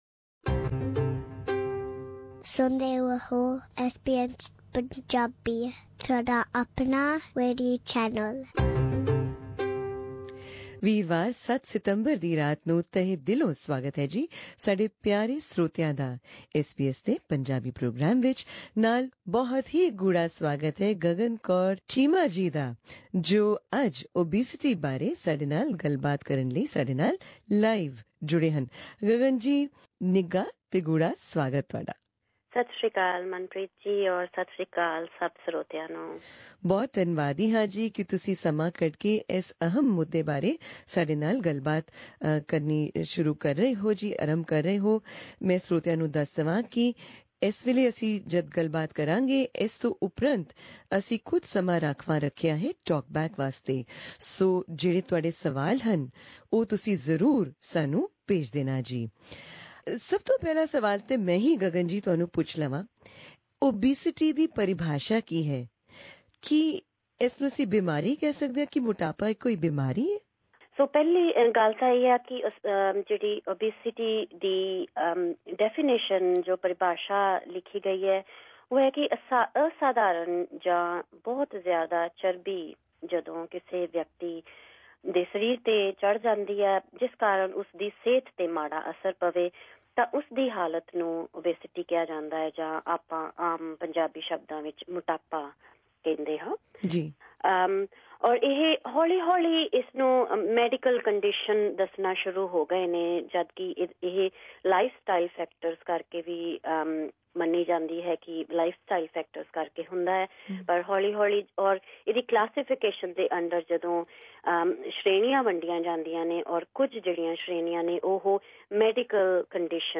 Above is an audio link to that interview and talkback, in case you missed it.